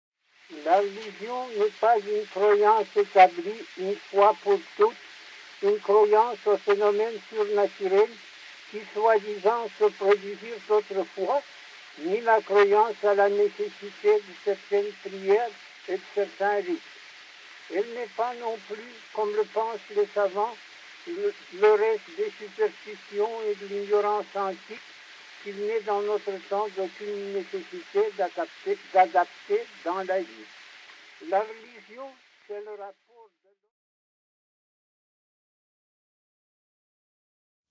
Tolstoï (1) lui-même d’un extrait tiré de son œuvre ou un chant liturgique interprété par Chaliapine (2).